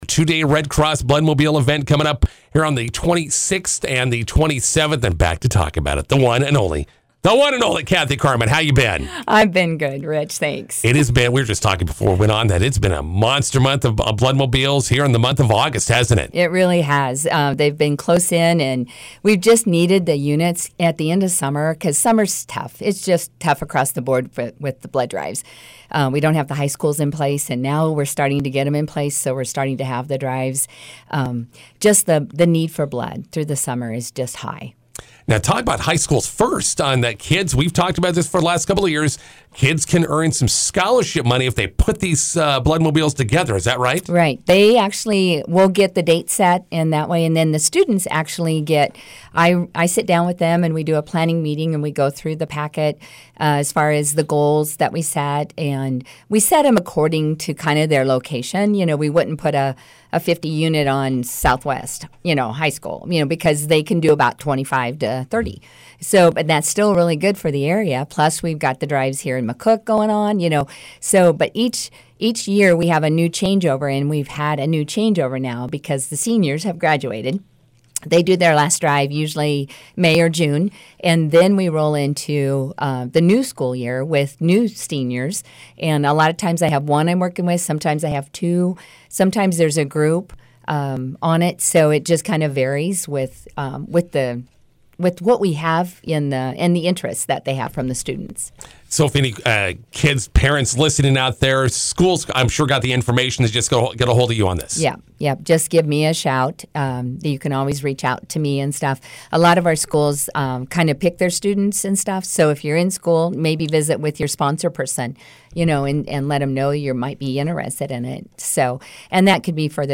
INTERVIEW: Sign-up for the two-day Red Cross Bloodmobile next week in McCook continues.